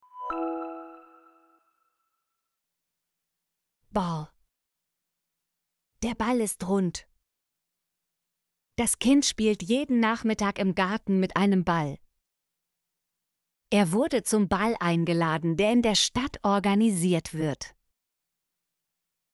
ball - Example Sentences & Pronunciation, German Frequency List